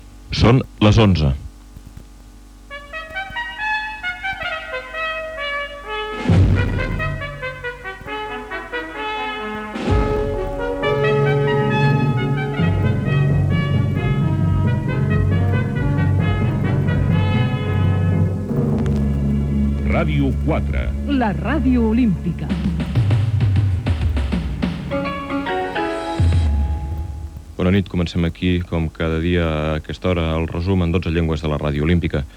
Hora i indicatiu de l'emissora en català.